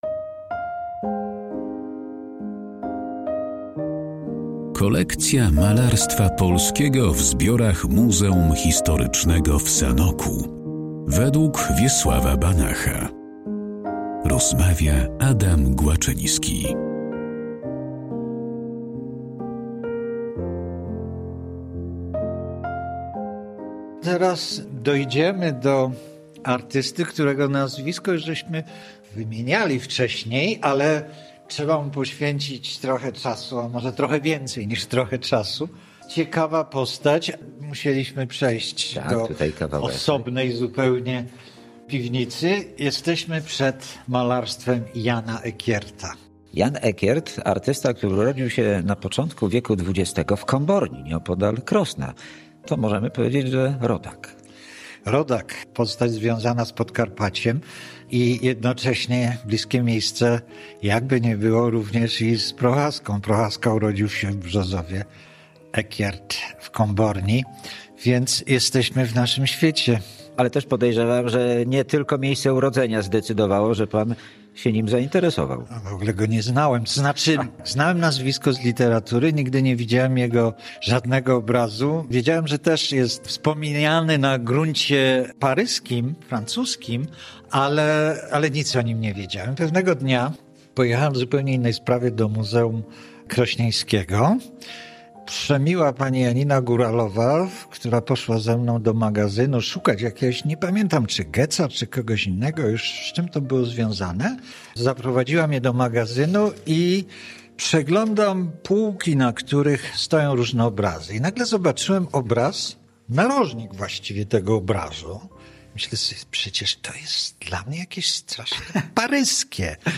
O twórczości Jana Ekierta i jego pracach znajdujących się w Muzeum Historycznym w Sanoku rozmawiają: